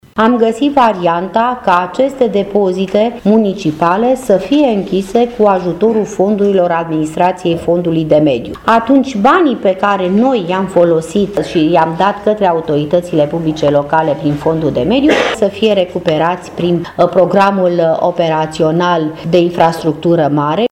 Ministrul Grațiela Gavrilescu a spus cum s-a decis, în cadrul întâlnirii, să fie rezolvată această situație neplăcută: